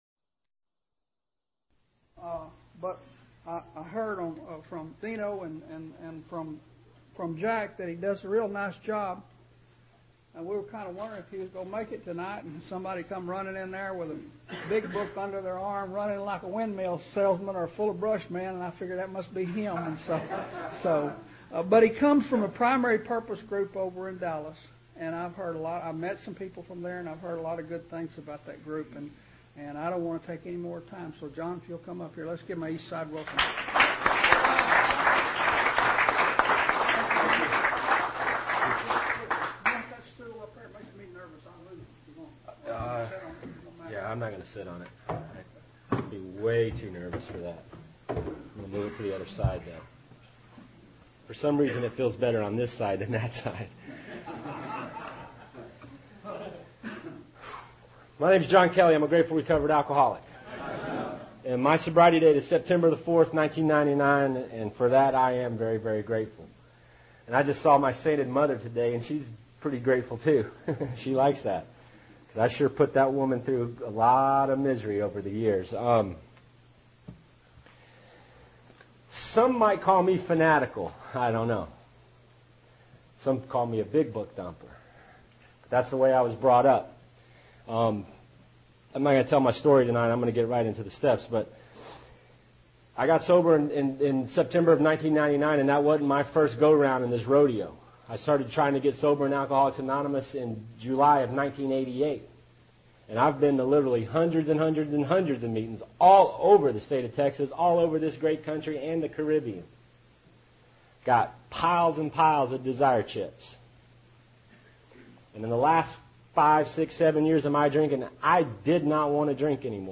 Worth, TX; 2006 | AA Speakers
Speaker Tape